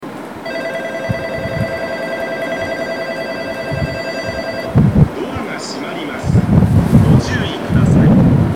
長浦駅　Nagaura Station ◆スピーカー：小VOSS
発車メロディーは導入されておらず、発車の際、発車ベルが流れます。
1番線発車ベル